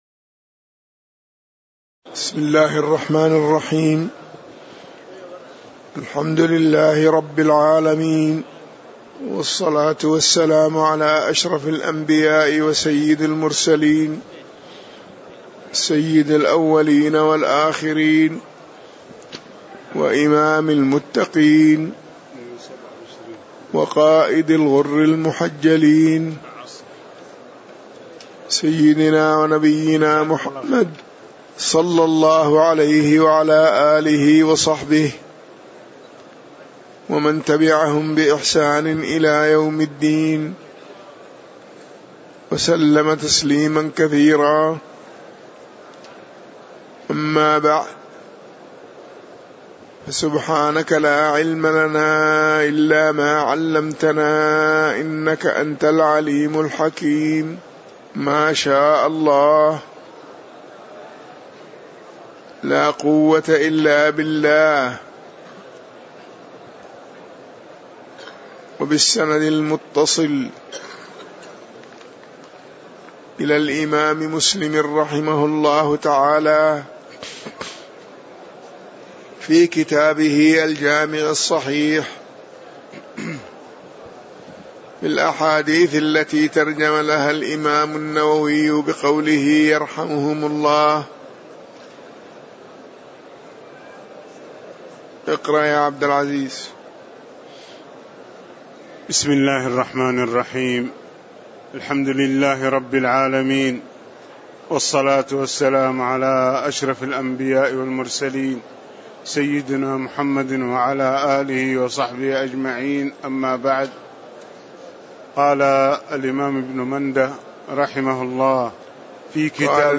تاريخ النشر ٦ رمضان ١٤٣٨ هـ المكان: المسجد النبوي الشيخ